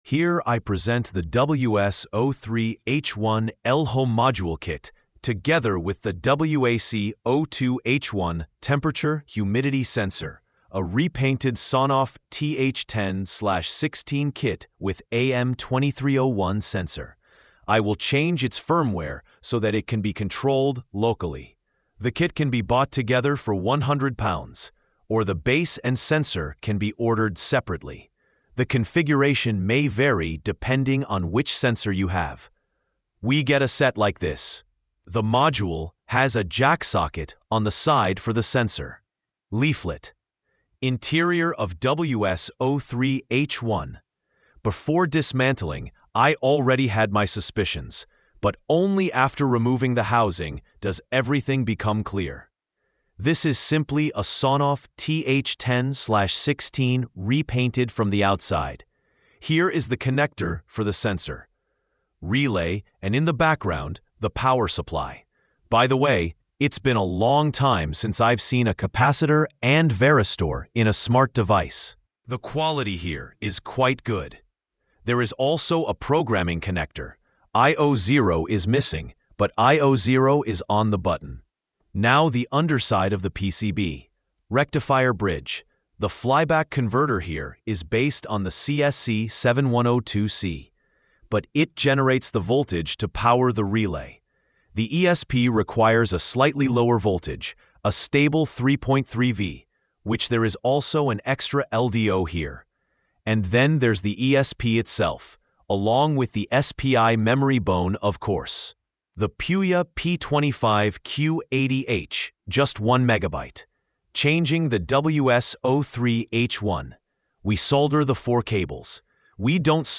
📢 Listen (AI):